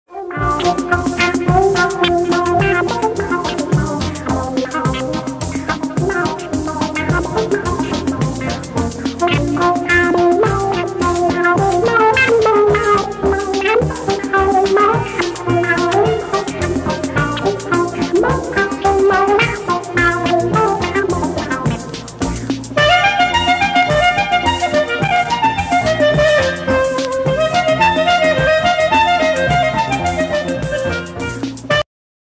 original clean sample